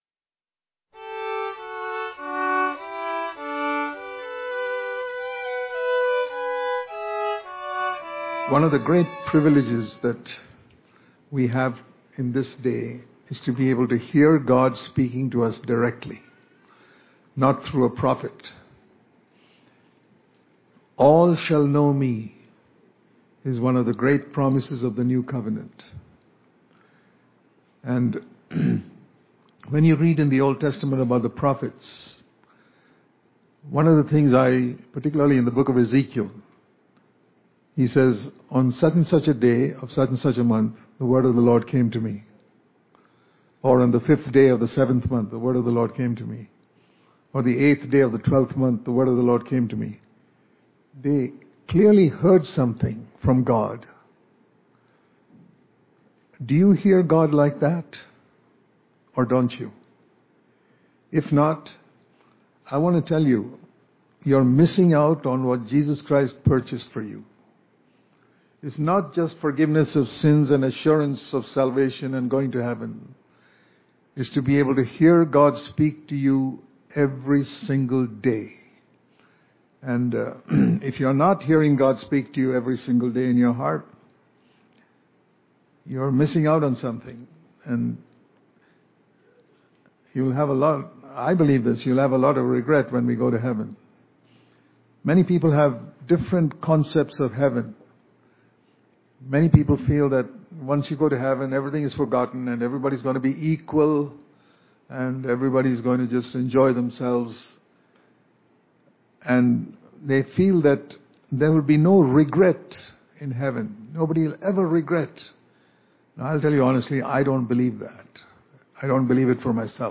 October 20 | Daily Devotion | Live Fully For Christ With The Help Of The Holy Spirit Daily Devotion